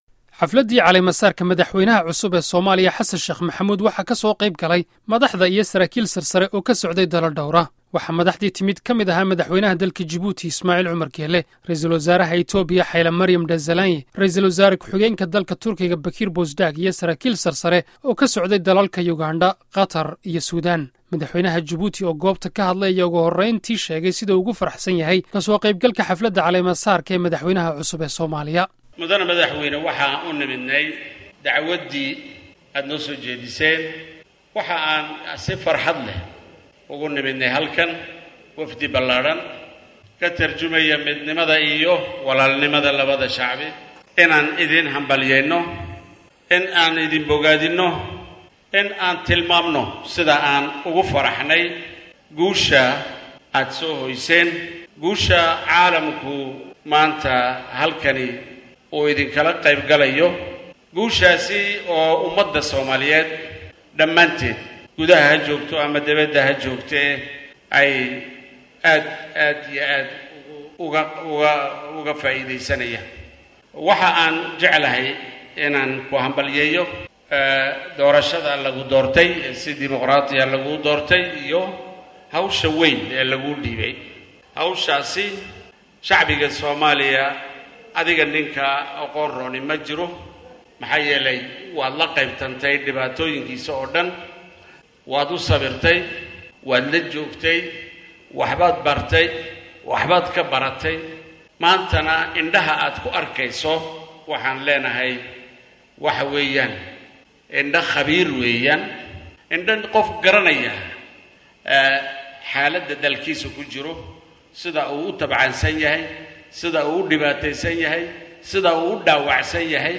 Xaflad caalemo-saar ah oo ka dhacday dugsigii hore ee Booliiska, isla markaana ay kasoo qeyb galeen madax caalamka ka socota ayaa Madaxweyne Xasan Sheekh uu xilka madaxweynenimo kula wareegay.